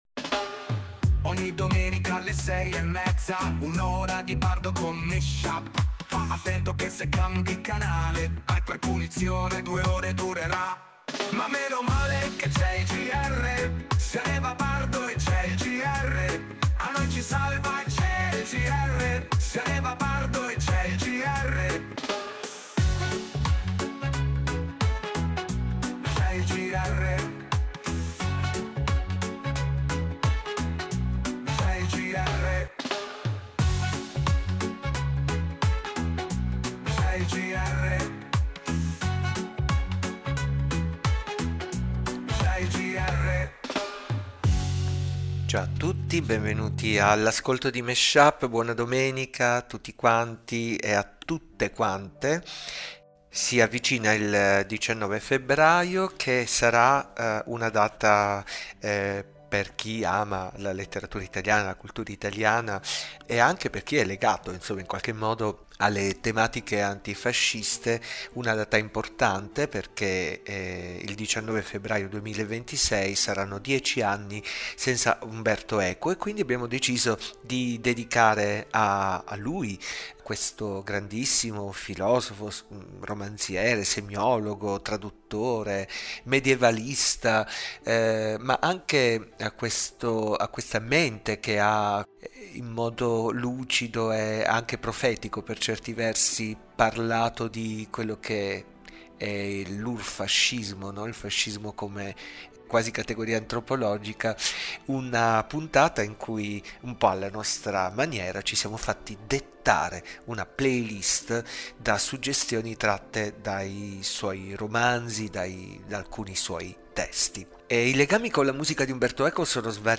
dj set tematico di musica e parole